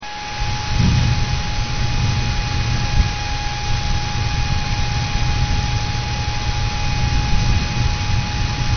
Noise
If you've read any other FOP-38 reviews or roundups, you'll note that all of the reviewers mention how unbearably loud the fan is. The FOP-38 comes stocked with a Delta 7000RPM fan, and let me tell you something; this thing sounds like a bloody hair dryer. I've taken the liberty of recording a sound clip of this beast in action.